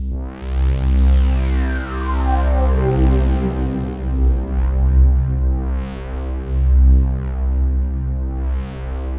Index of /90_sSampleCDs/Best Service ProSamples vol.36 - Chillout [AIFF, EXS24, HALion, WAV] 1CD/PS-36 AIFF Chillout/AIFF Synth Atmos 2